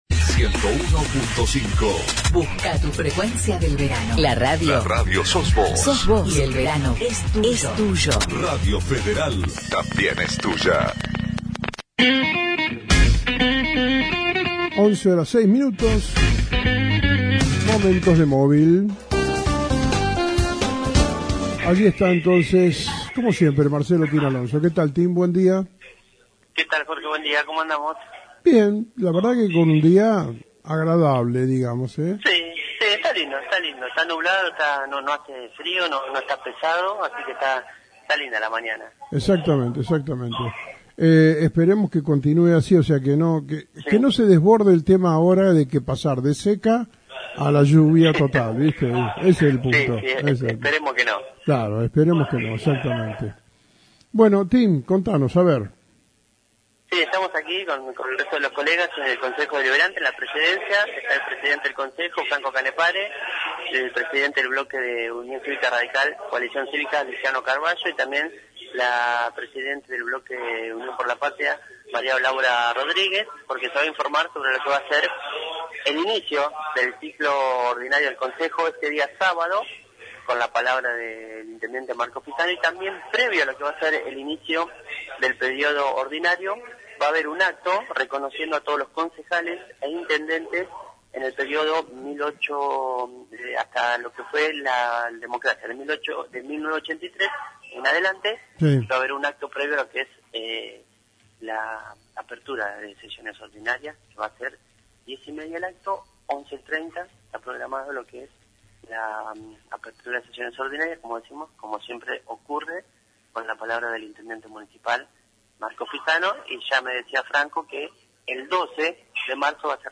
Apertura de las Sesiones Ordinarias del Concejo Deliberante de Bolívar :: Radio Federal Bolívar
Presidente Dr. Franco Canepare, la Concejal María Laura Rodriguez y el Concejal Luciano Carballo Laveglia